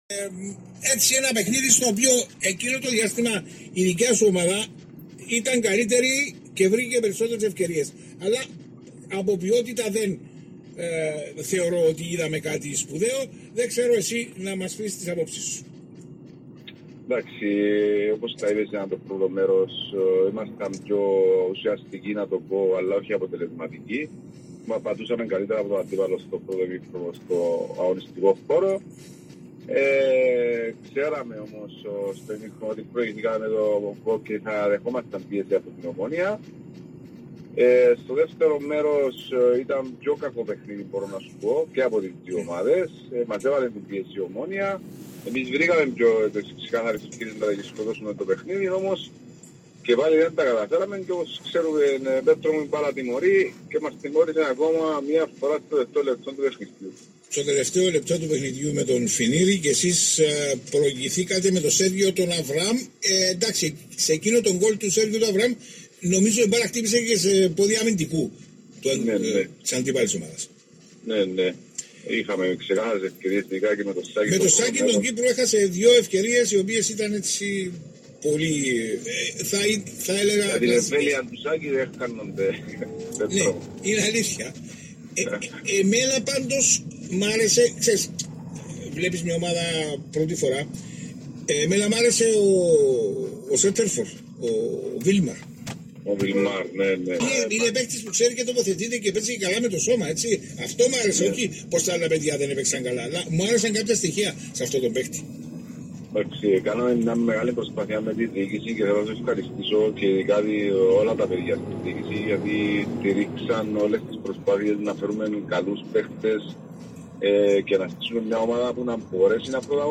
Δηλώσεις στον ΣΠΟΡ ΦΜ 95 και στην εκπομπή “Πίσω απο τη βιτρίνα”
aradippou-vs-omonoia-dilwseis-1.mp3